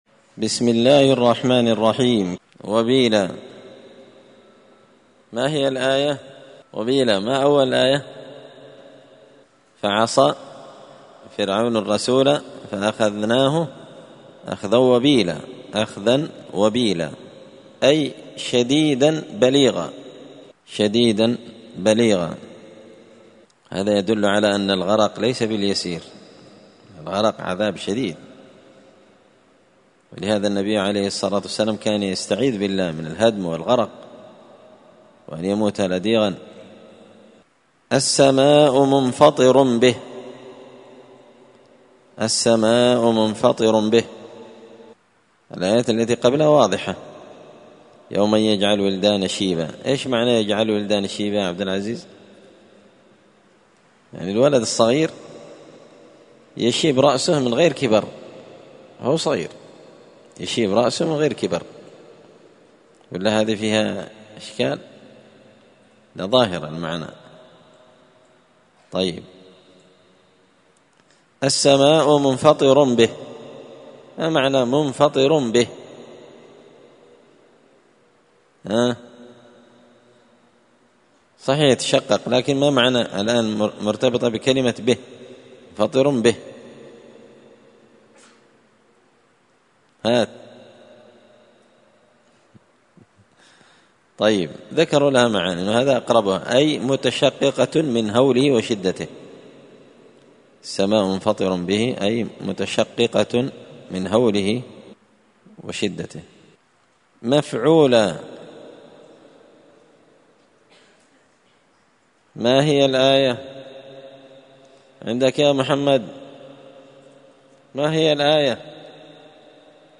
الثلاثاء 6 صفر 1445 هــــ | الدروس، دروس القران وعلومة، زبدة الأقوال في غريب كلام المتعال | شارك بتعليقك | 11 المشاهدات